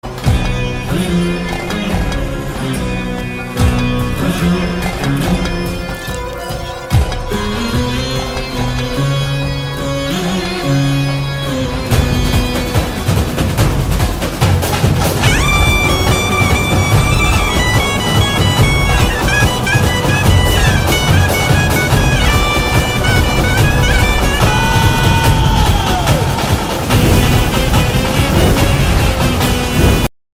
latest trending cinematic sound.